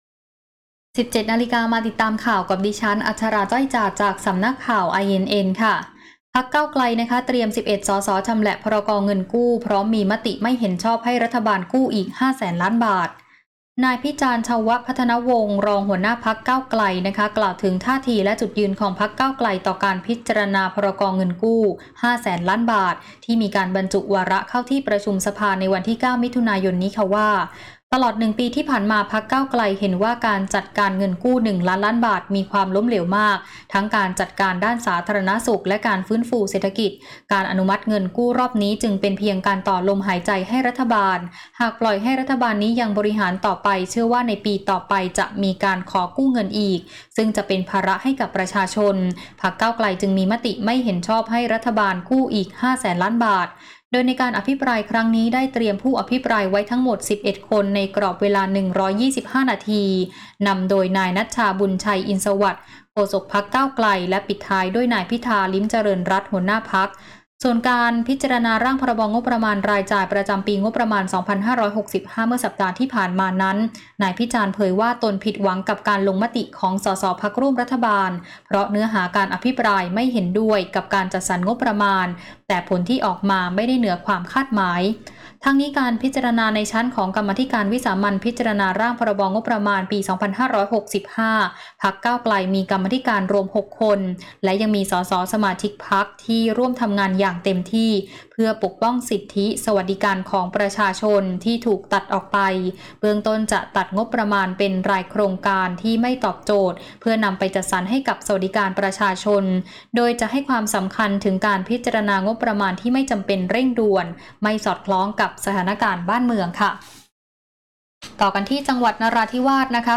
ข่าวต้นชั่วโมง 17.00 น.